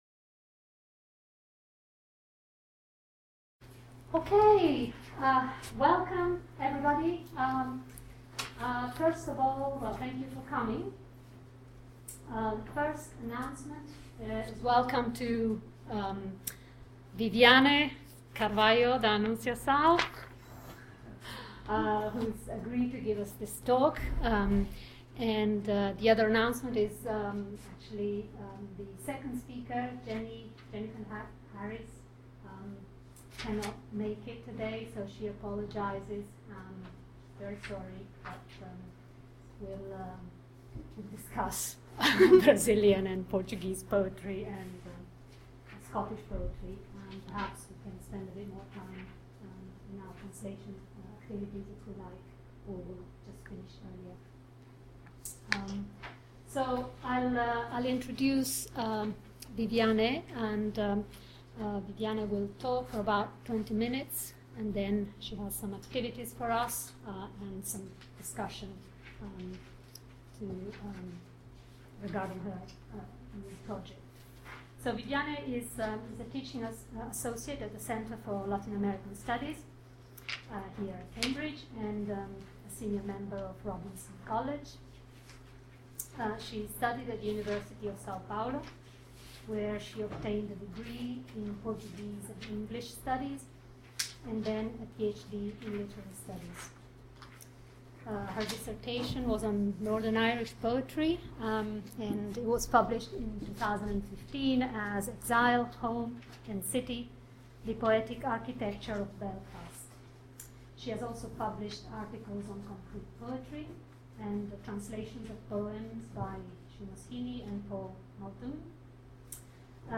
A series of panel discussions and workshops will bring together practitioners and scholars from fields as diverse as literary studies, linguistics, theology, history, music, philosophy, and anthropology to consider and respond to what we anticipate will be provocative insights from our invited speakers.